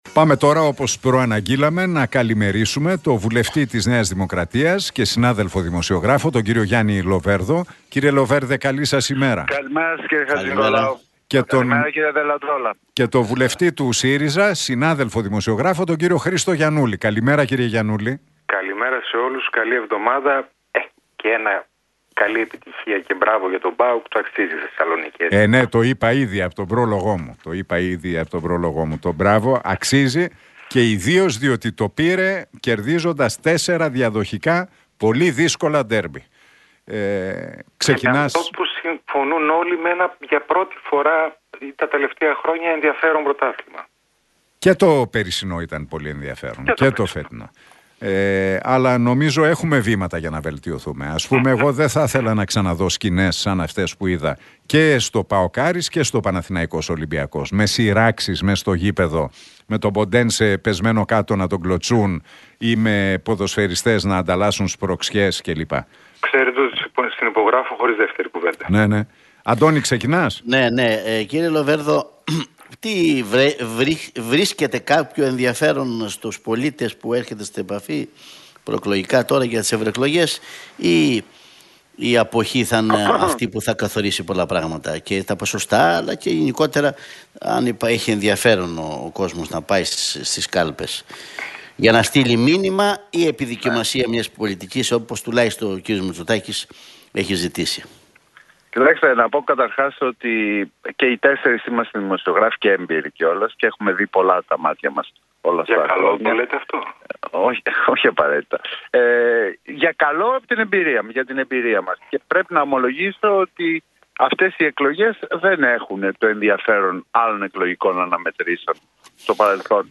Debate Λοβέρδου – Γιαννούλη στον Realfm 97,8 για ακρίβεια, Συμφωνία των Πρεσπών και Τουρκία